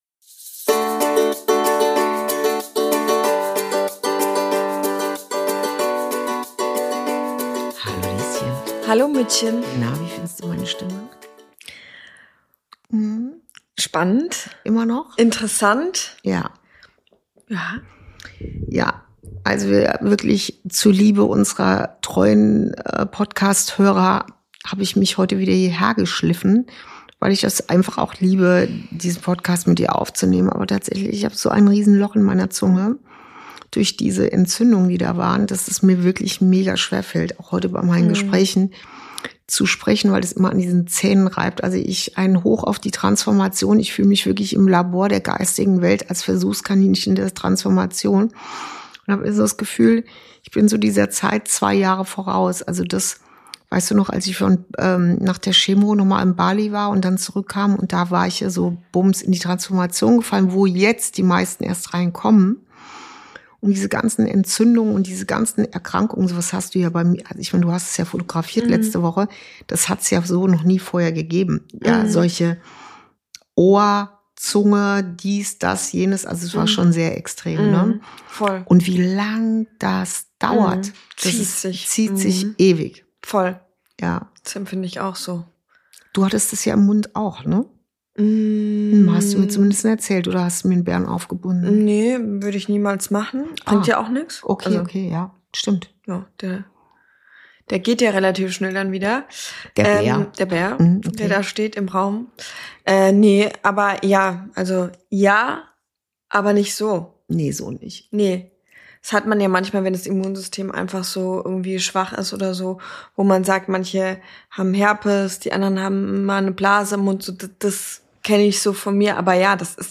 Folge 39: Jetzt wird’s echt – Freundschaften im Wandel ~ Inside Out - Ein Gespräch zwischen Mutter und Tochter Podcast